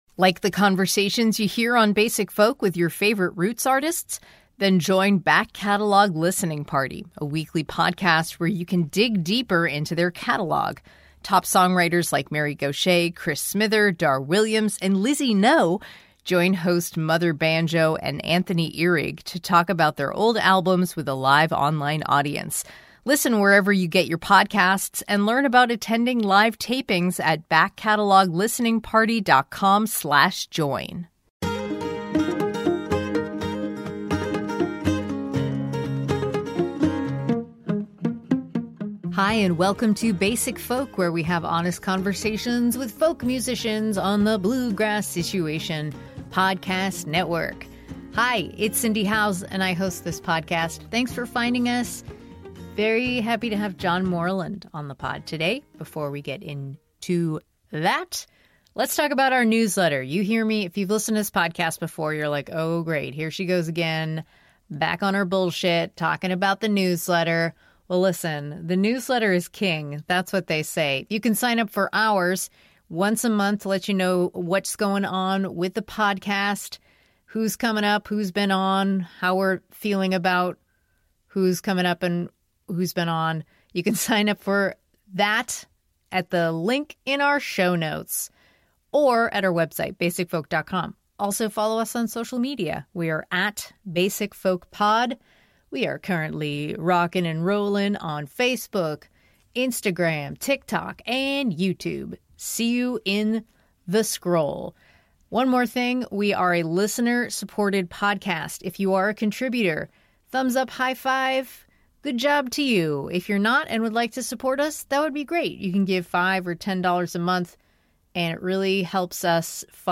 LISTEN: APPLE • SPOTIFY • AMAZON • MP3 During our Basic Folk chat, Moreland talks about the process of unraveling himself from his smartphone, reconnecting with the acoustic guitar, and getting to know himself again during this period of quiet. He talks about how playing live is very vulnerable for him, to the point where he started taking (and loving) beta-blockers to stave off anxiety and adrenaline.